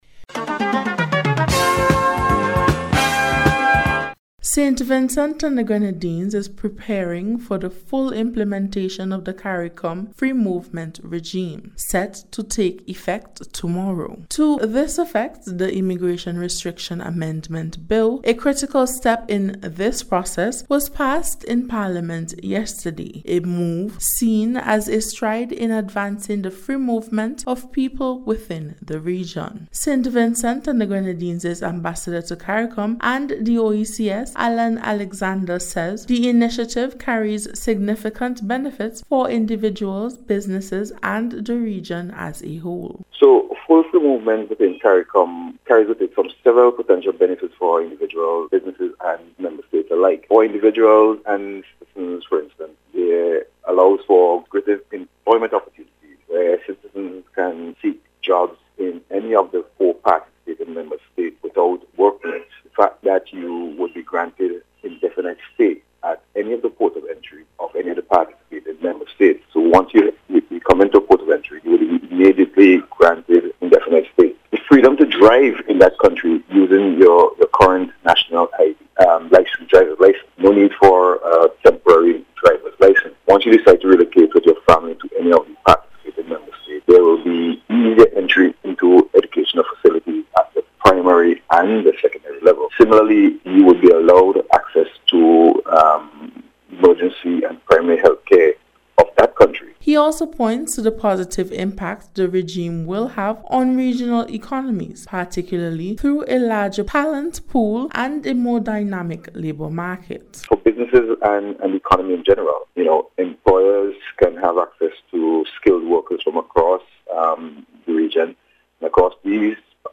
NBC’s Special Report- Tuesday 30th September,2025